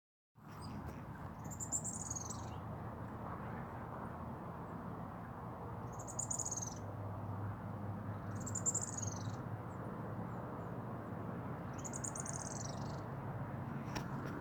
Olive Spinetail (Cranioleuca obsoleta)
Detailed location: Parque cascada del caracol
Condition: Wild
Certainty: Photographed, Recorded vocal
Curutie-olivaceo_1.mp3